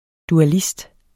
Udtale [ duaˈlisd ]